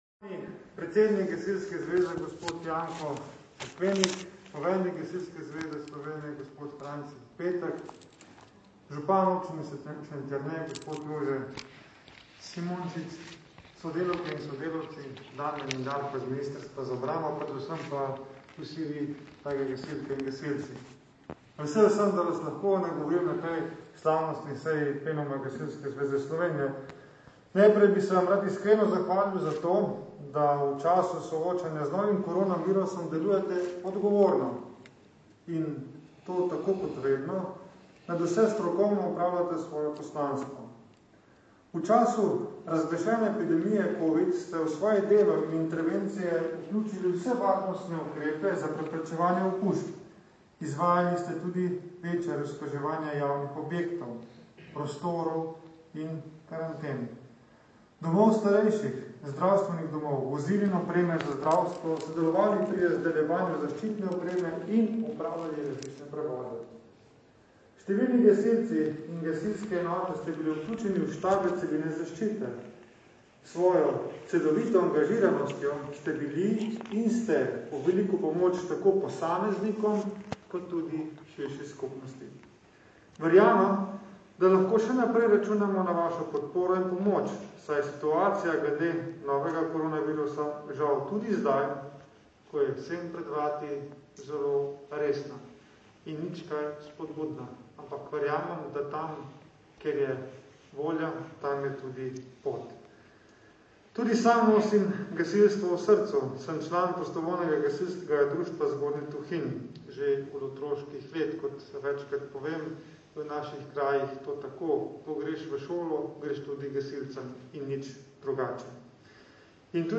V kulturnem centru Primoža Trubarja v Šentjerneju je v soboto, 19. septembra dopoldan, potekala slavnostna seja Plenuma Gasilske zveze Slovenije na kateri je zbrane nagovoril minister za obrambo mag. Matej Tonin.
Zvočni posnetek govora ministra Tonina (MP3)